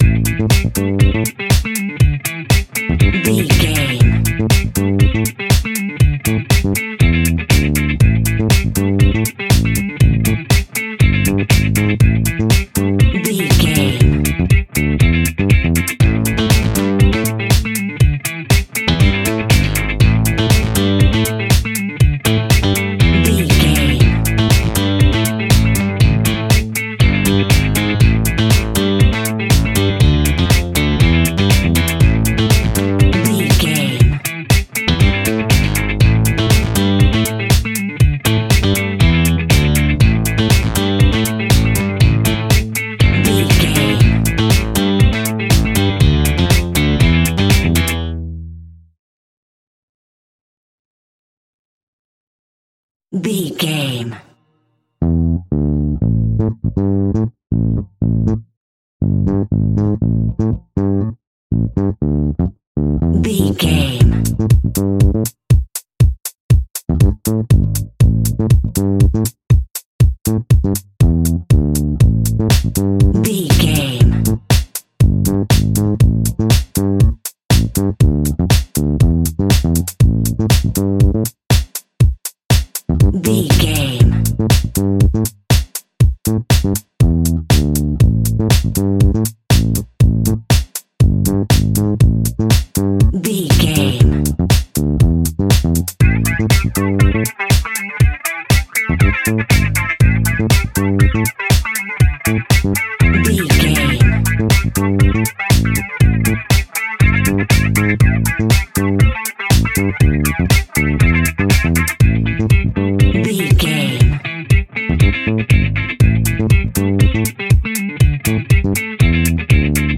Aeolian/Minor
funky
groovy
uplifting
driving
energetic
bass guitar
electric guitar
drums
synthesiser
electric organ
brass
funky house
disco house
electronic funk
upbeat
synth leads
Synth Pads
synth bass
drum machines